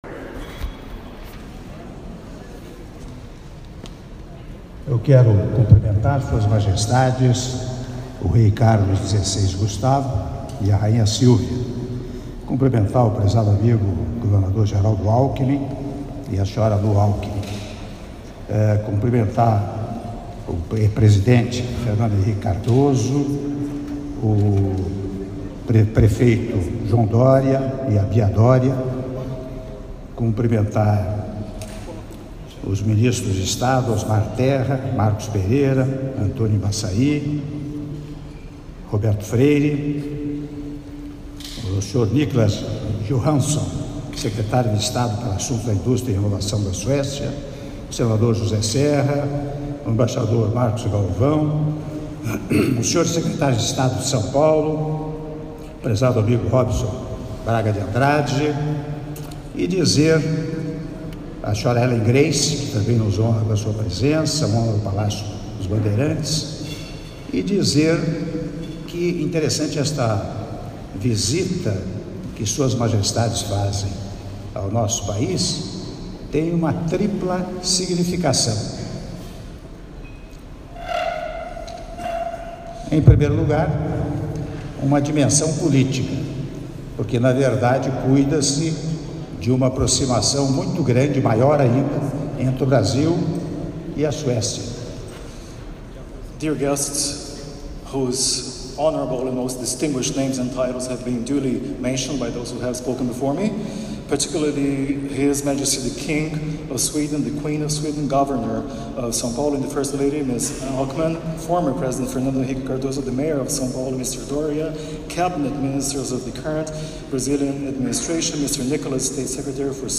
Áudio do discurso do Presidente da República, Michel Temer, durante jantar oferecido pelo governador de São Paulo aos reis da Suécia, Carlos XVI Gustavo e Silvia - São Paulo/SP (08min01s)